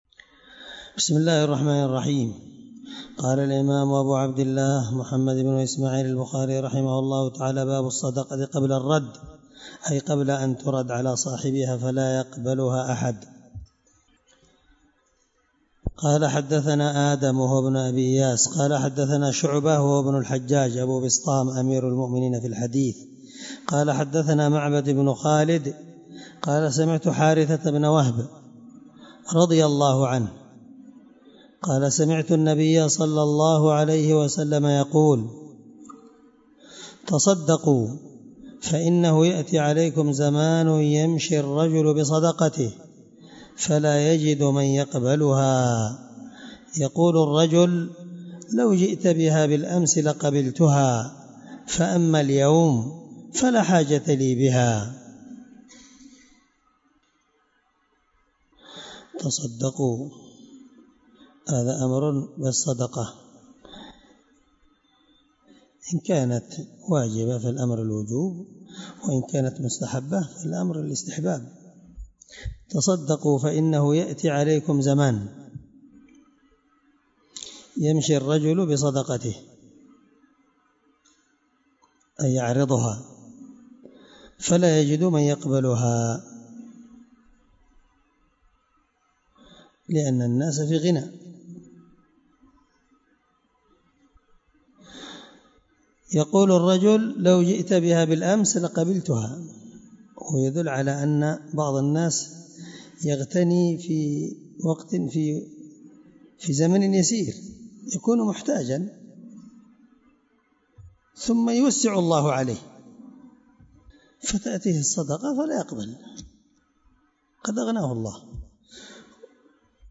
الدرس 10من شرح كتاب الزكاة حديث رقم(1411-1412 )من صحيح البخاري